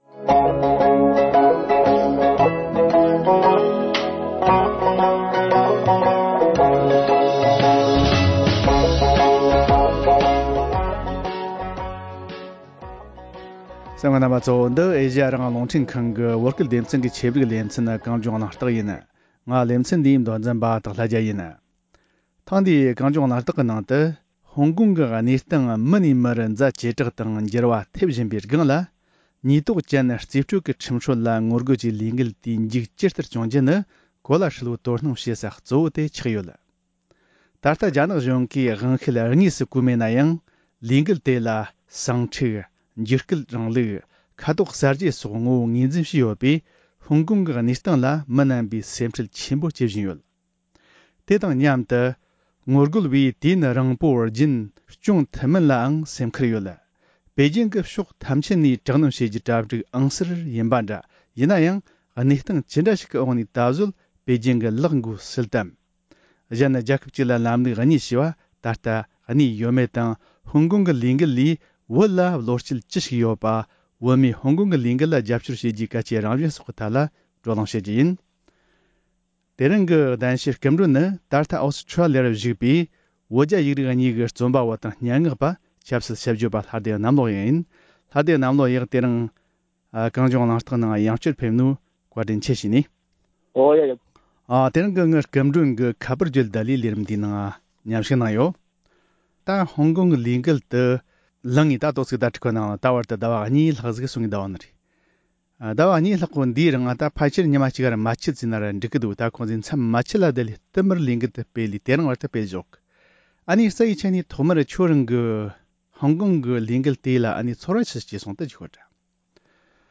ཧོང་ཀོང་གི་ལས་འགུལ་ལས་བློ་བསྐྱེད་ཅི་ཞིག་ཡོད་པ་དང་བོད་མིས་ཧོང་ཀོང་གི་ལས་འགུལ་ལ་རྒྱབ་སྐྱོར་བྱེད་རྒྱུའི་གལ་ཆེའི་རང་བཞིན་སོགས་ཀྱི་ཐད་གླེང་མོལ་ཞུས་པ།